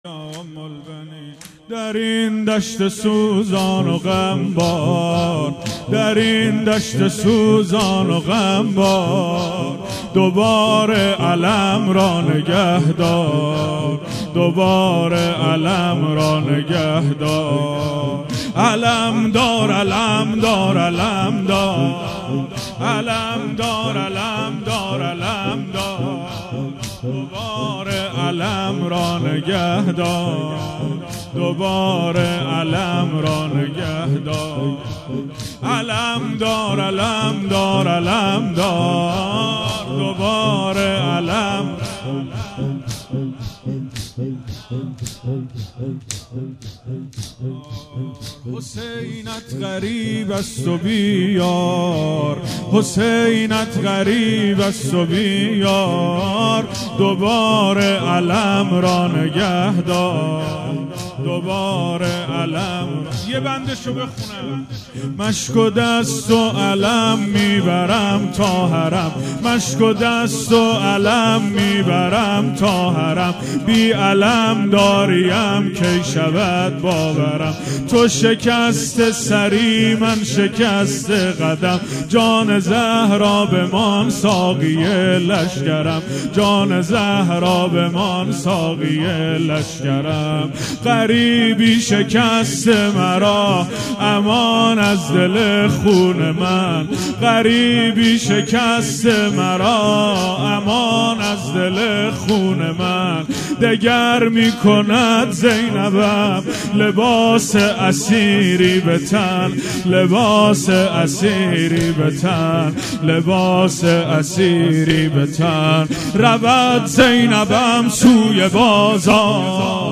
مداحی زمینه
مراسم هفتگی و وفات حضرت ام البنین(سلام الله علیها)17بهمن1398